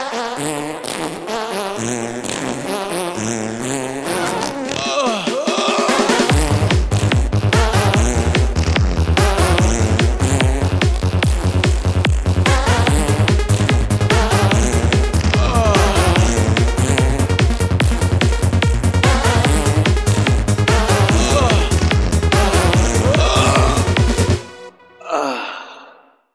Nokia Funny Tone